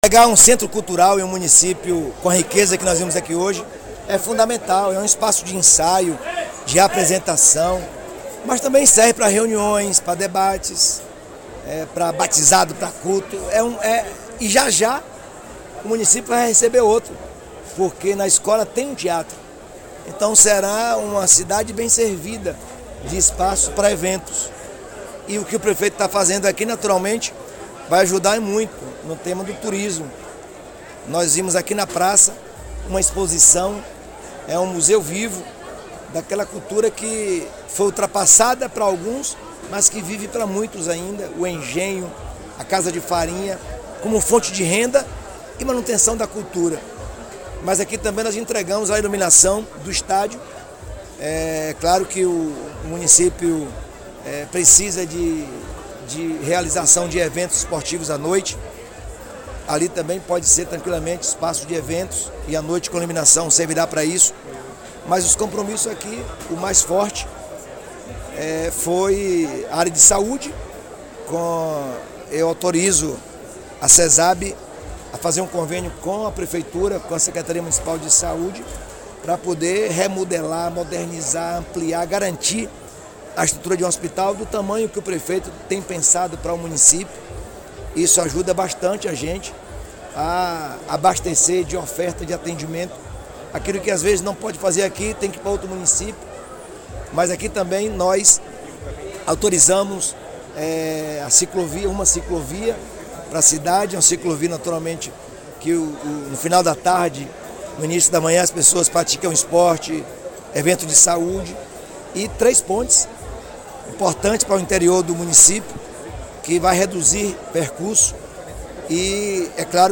🎙Governador Jerônimo Rodrigues